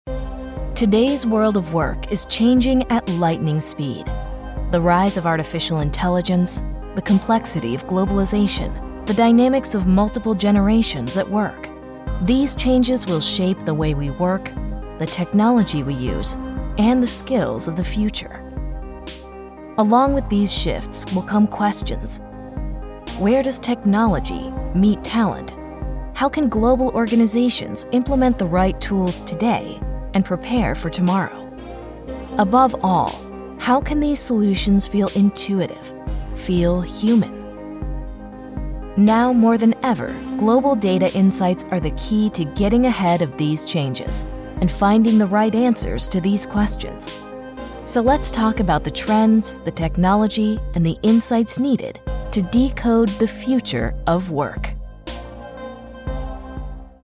Commercieel, Natuurlijk, Vriendelijk, Warm, Zakelijk
Corporate